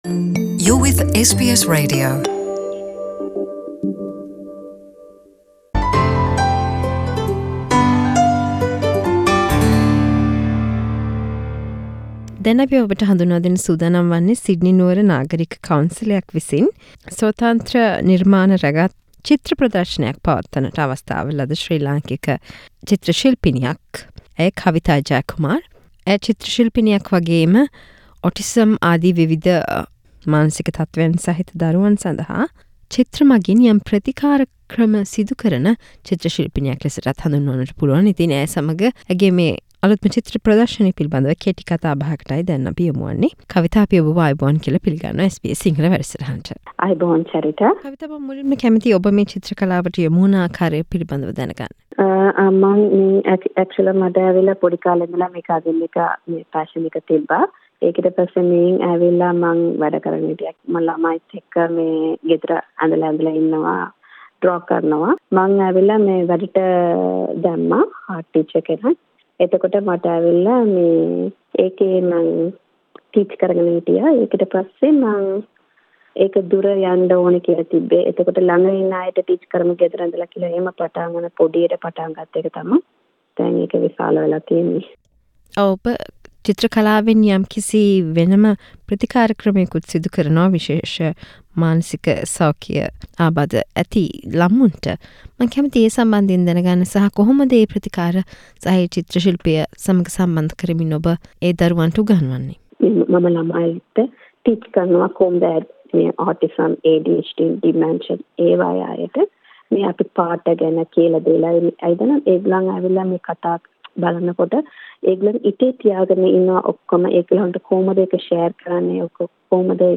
A chat with Sri Lankan artist in Sydney